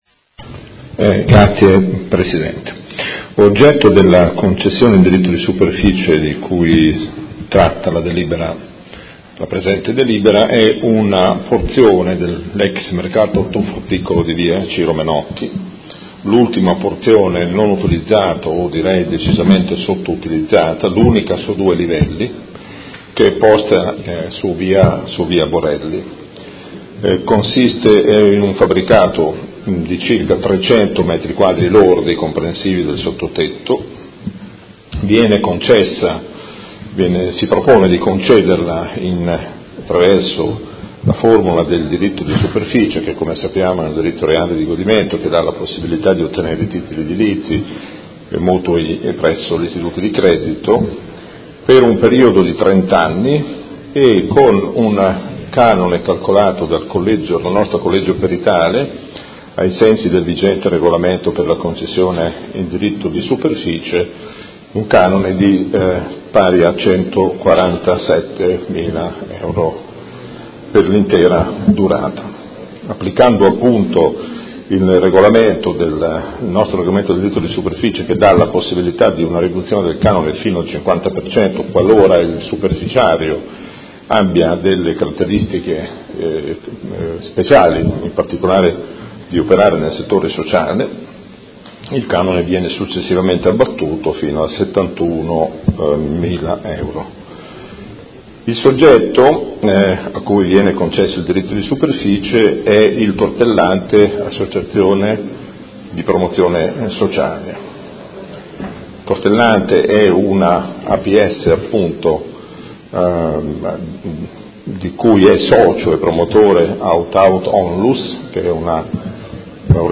Gabriele Giacobazzi — Sito Audio Consiglio Comunale